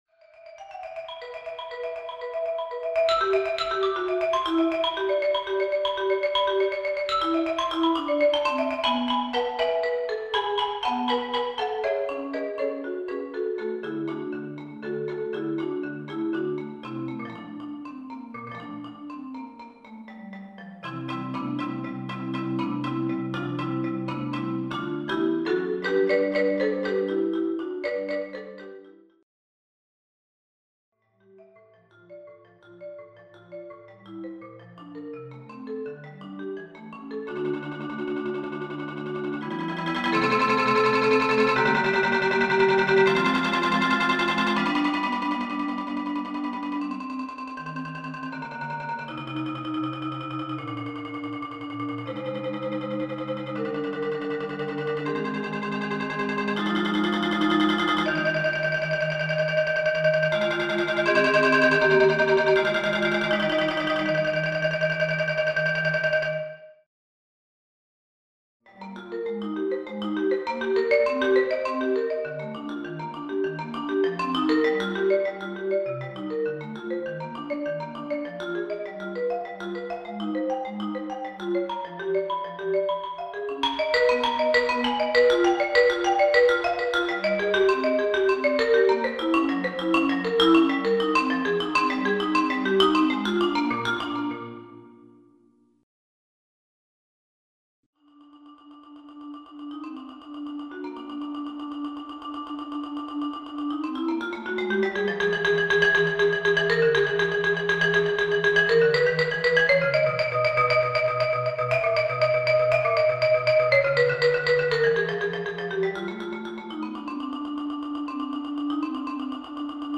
Four etudes for the intermediate marimbist.